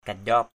/ka-ʄɔ:p/ (t.) rít, nhớp (da) = visqueux, collant (peau). kalik kanjaop kl| k_W<P da rít, da nhớp.